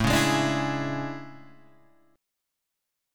A Major 11th
AM11 chord {5 4 6 7 5 4} chord